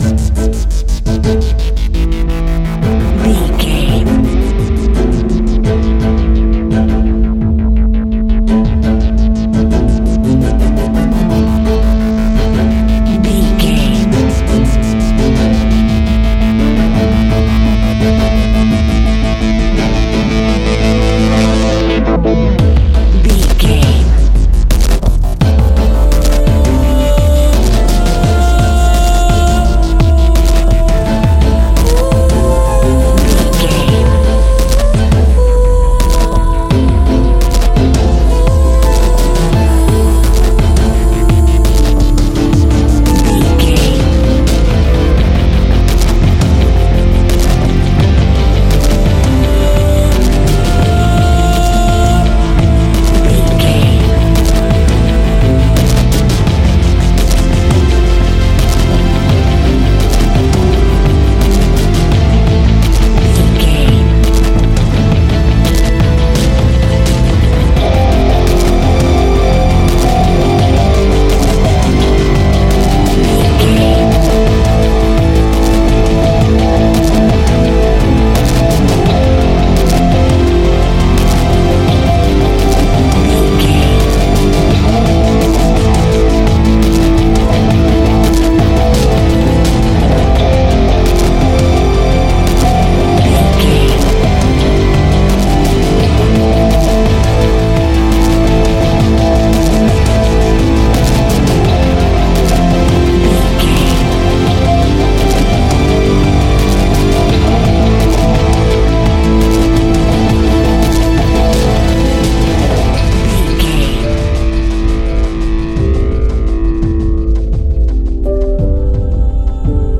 Aeolian/Minor
dramatic
epic
strings
percussion
synthesiser
brass
violin
cello
double bass